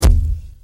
mortarbd.mp3